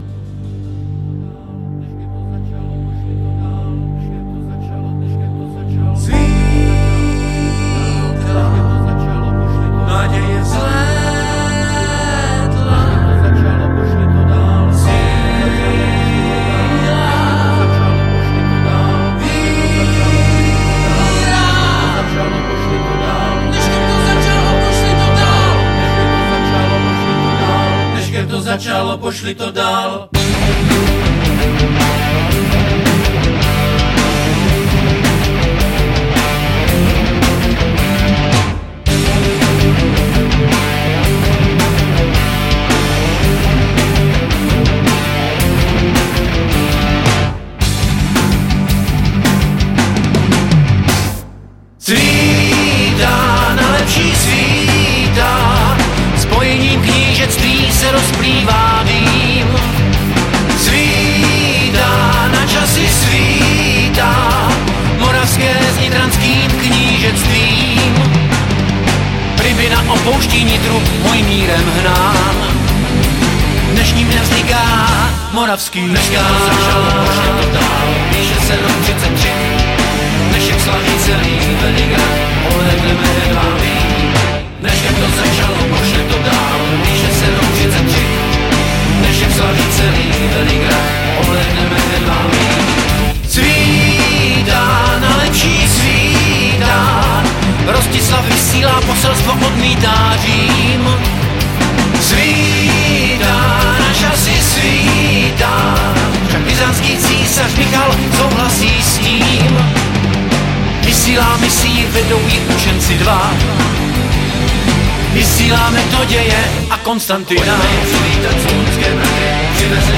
Žánr: Rock